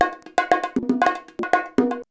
119BONG12.wav